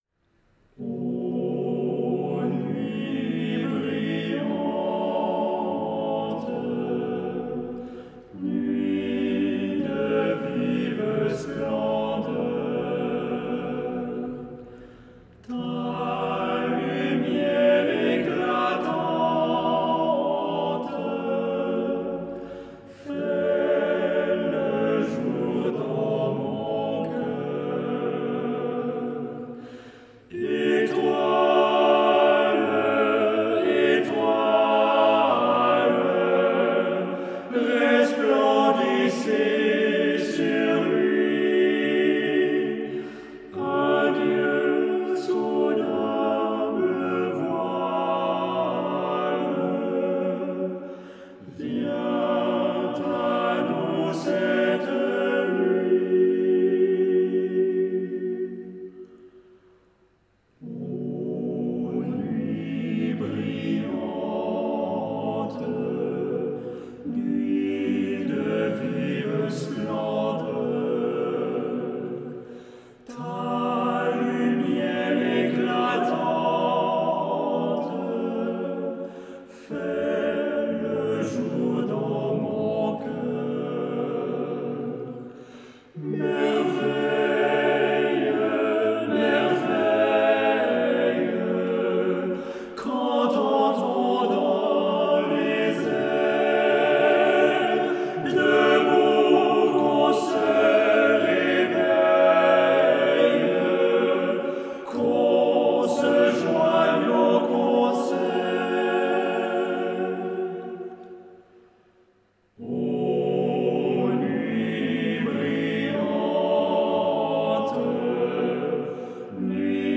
Il est l’auteur de nombreuses harmonisations, dont le vieux Noël O nuit brillante. Il en a livré plusieurs versions : pour une voix et piano ; chœur à trois voix égales ; chœur à quatre voix égales ou mixtes ; chœur pour quatre voix d’hommes, la version interprétée ici.